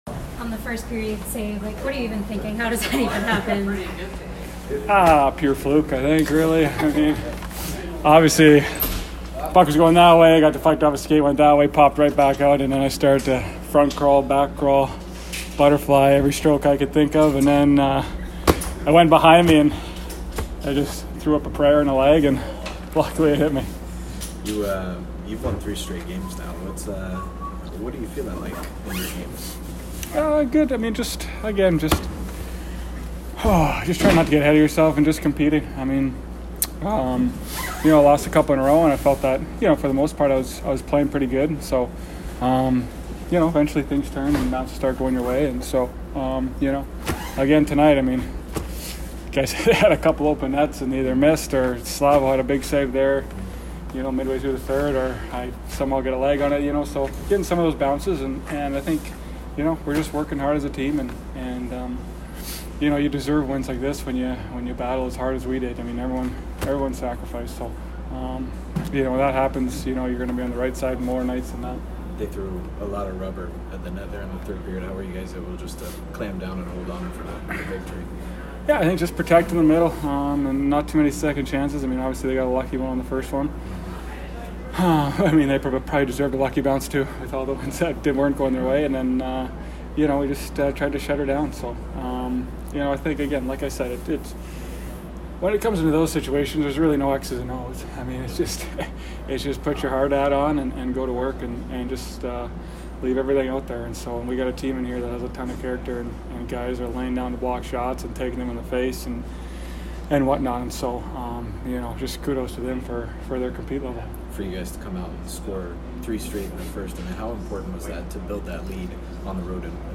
Mrazek post-game 11/30